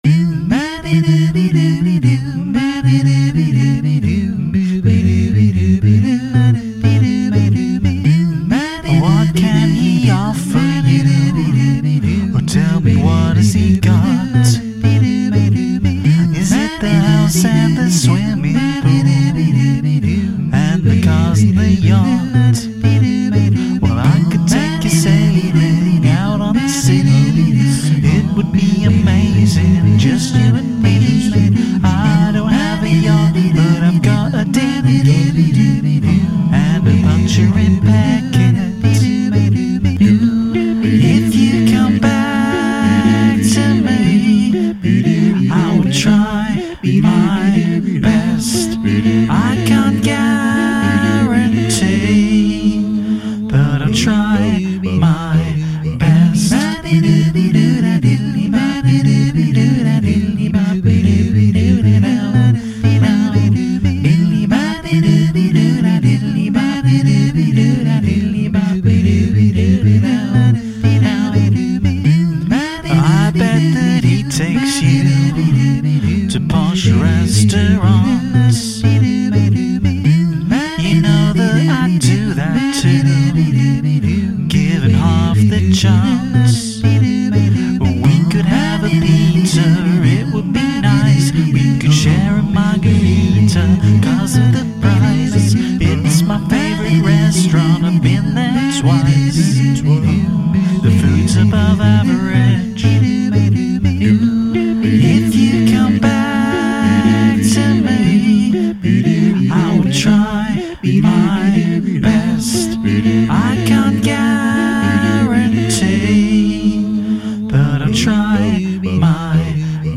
Puncture Repair Kit (A-capella)